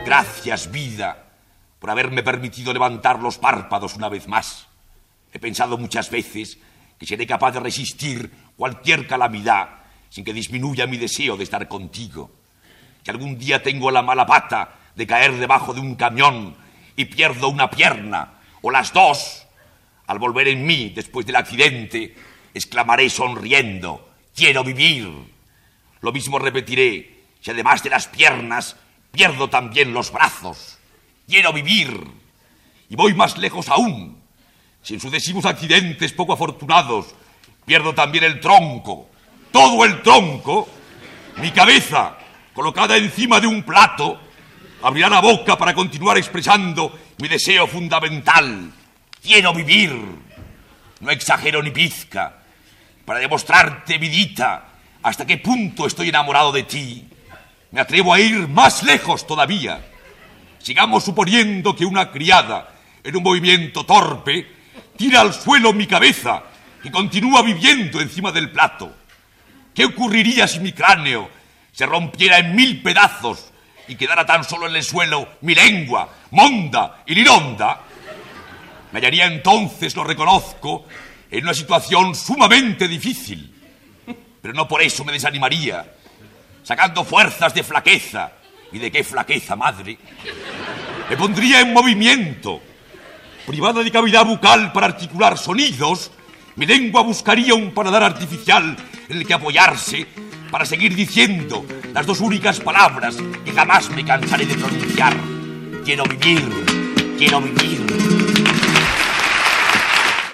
L'escriptor Álvaro de la Iglesia recita un escrit satíric seu al cicle "Alforjas para la poesía"
Fragment extret del programa "Audios para recordar" de Radio 5 emès el 5 d'abril de del 2013.